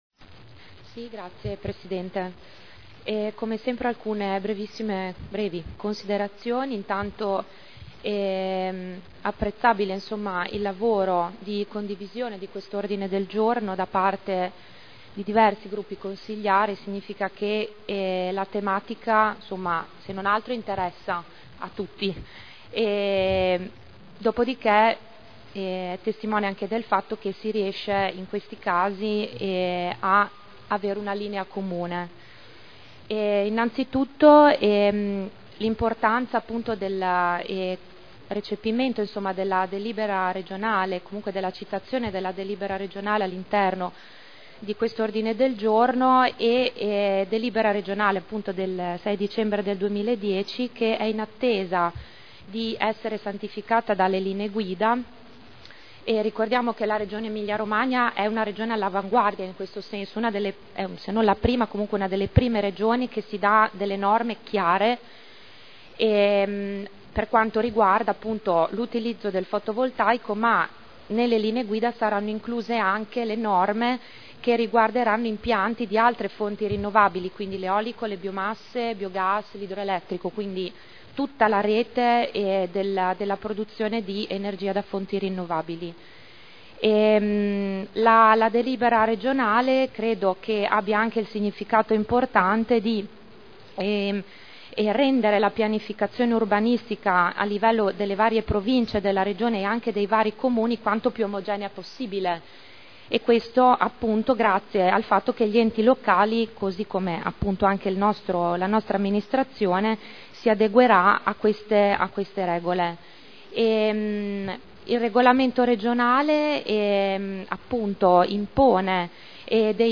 Seduta del 24/01/11. Dibattito su ordine del Giorno presentato in corso di seduta.